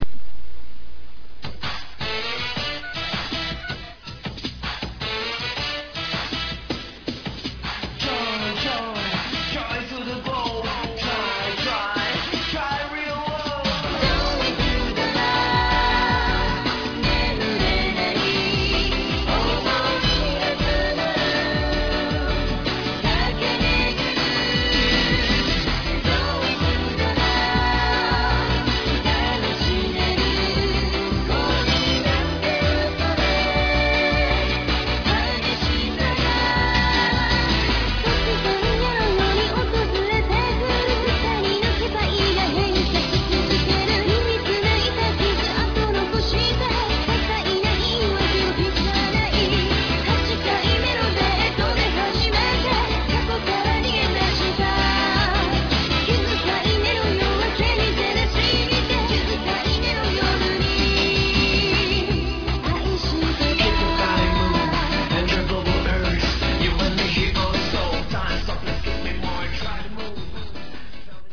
( 主唱兼鍵盤 )
( 女主唱 )
( RAPPER )